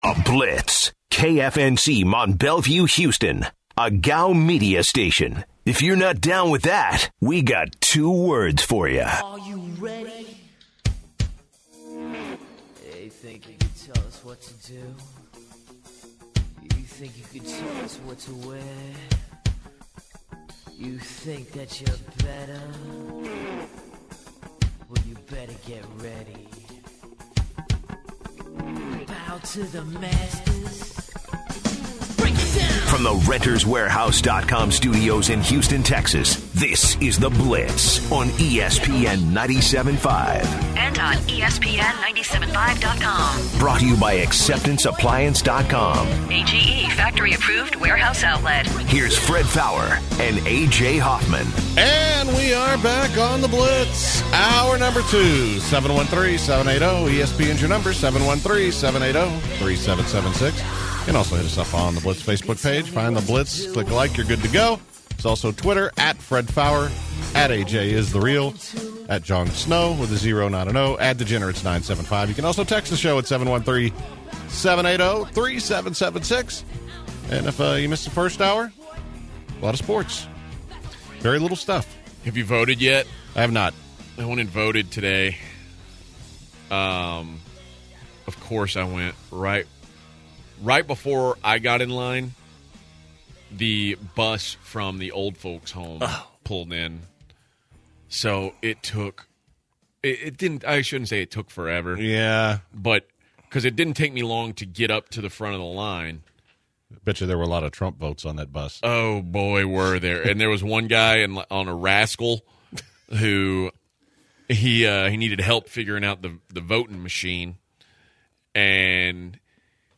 Also they take calls about QB contract talks.